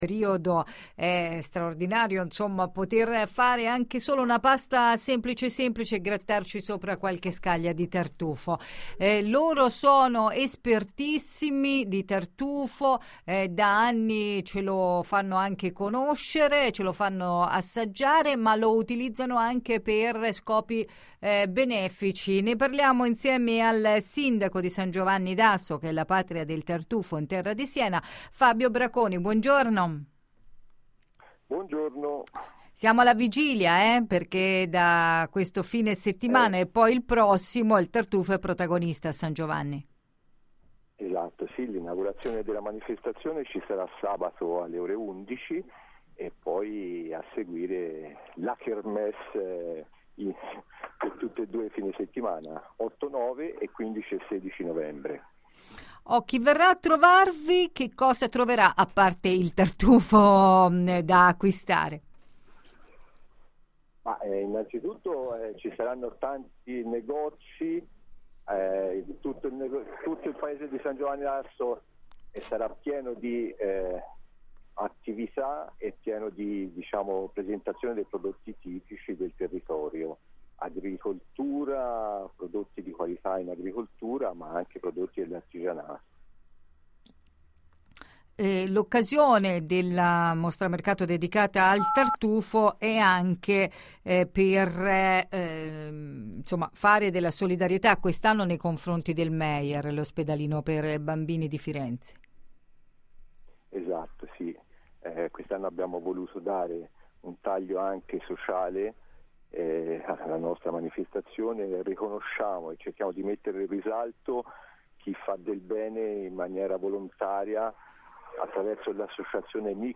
San Giovanni D'Asso mostra mercato del tartufo: il sindaco Fabio Braconi - Antenna Radio Esse